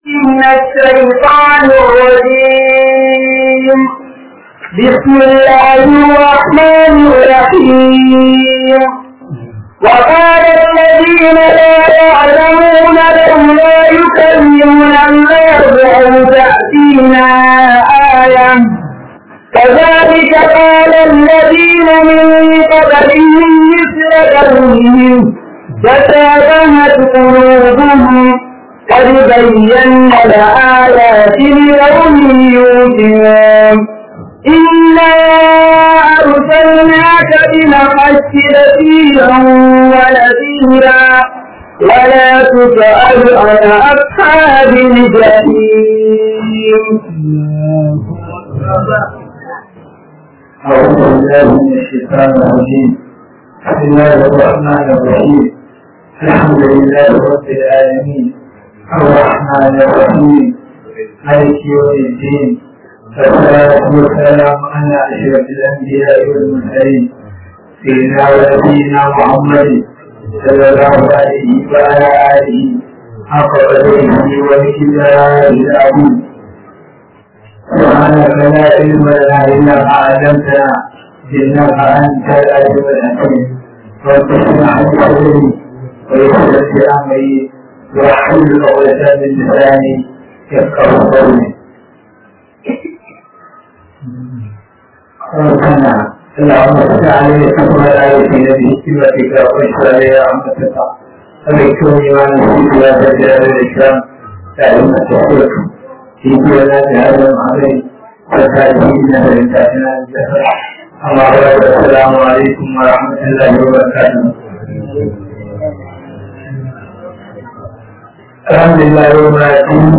RAMADAN TAFSIR 2024